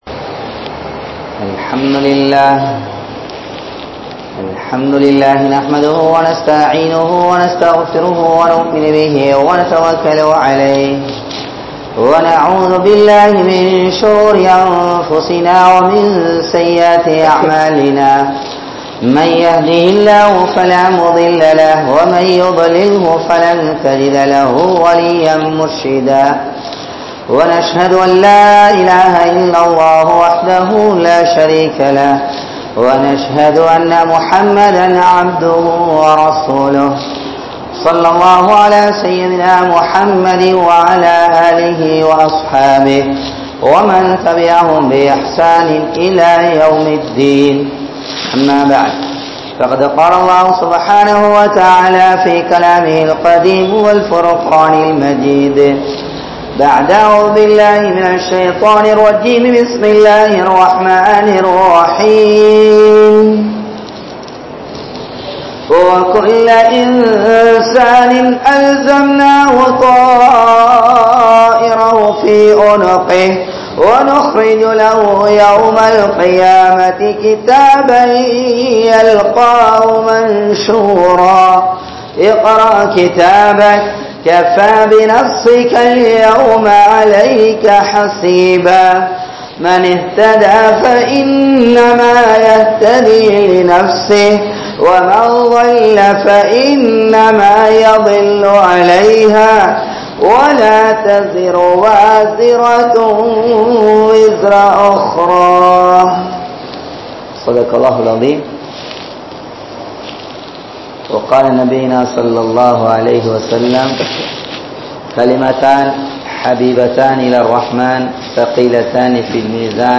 Qiyamath Naal (கியாமத் நாள்) | Audio Bayans | All Ceylon Muslim Youth Community | Addalaichenai
Wellampittiya, Safa Jumua Masjidh